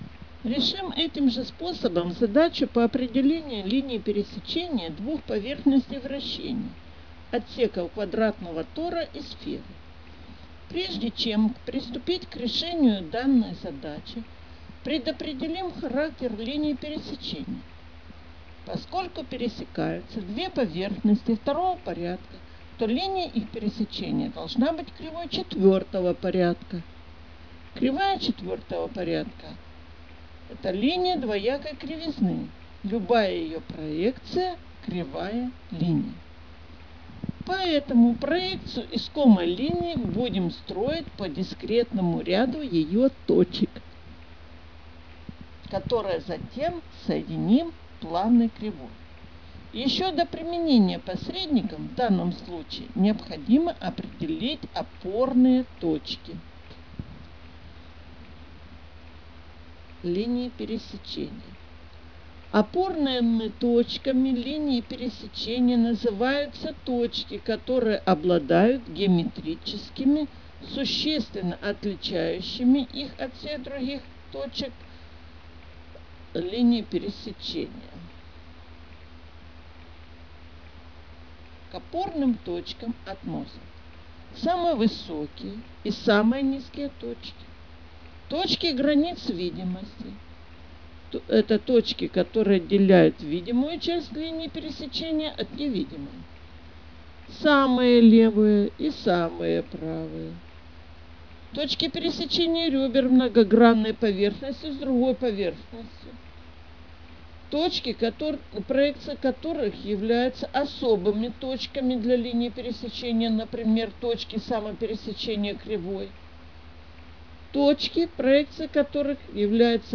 Аудио-комментарии